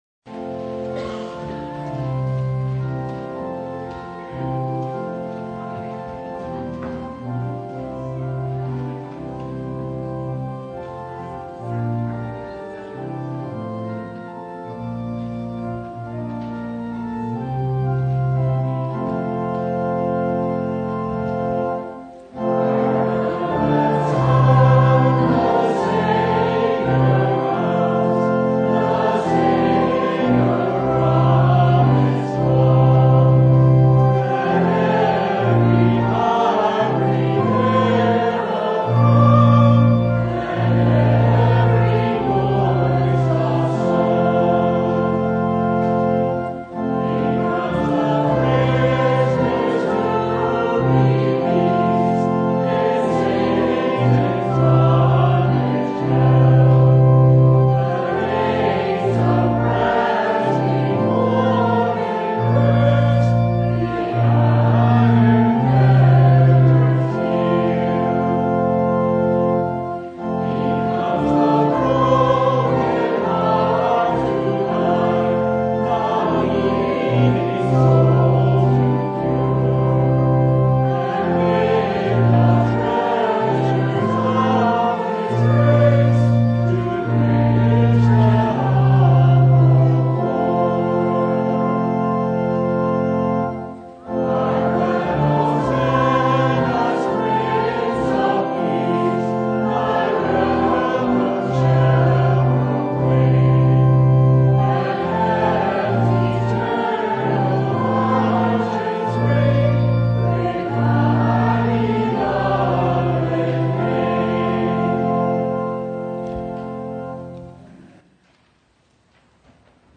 Service Type: Advent Vespers
Topics: Full Service